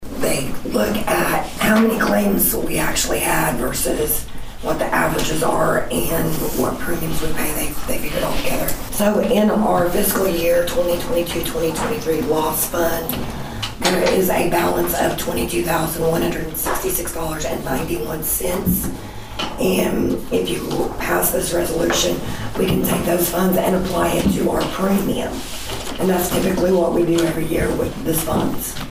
The Nowata City Commissioners held their final regularly scheduled meeting in 2025 on Monday evening at the Nowata Fire Department.